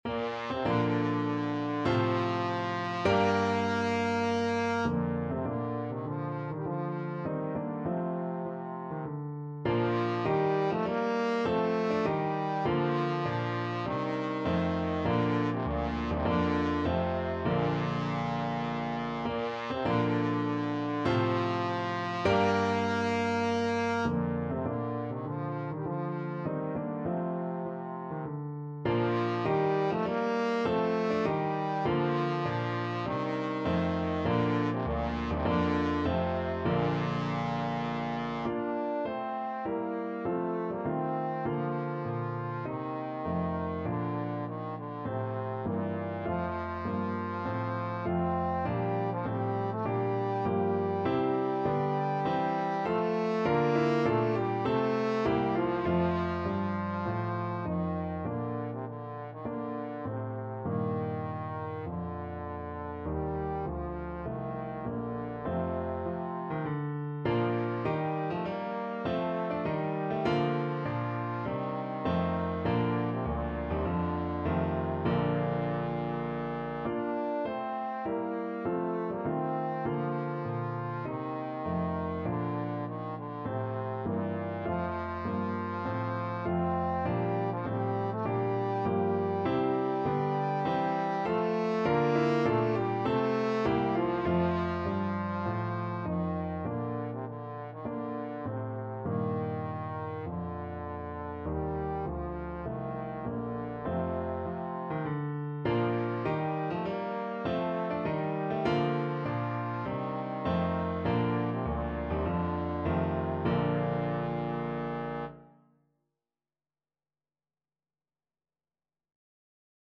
4/4 (View more 4/4 Music)
Bb3-D5
Classical (View more Classical Trombone Music)